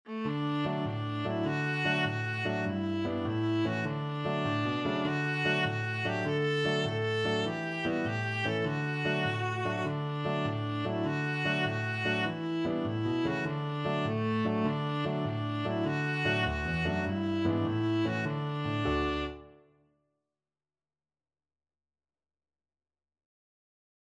Viola
Quick two in a bar . = c.100
A4-A5
D major (Sounding Pitch) (View more D major Music for Viola )
6/8 (View more 6/8 Music)
itsy_bitsy_VLA.mp3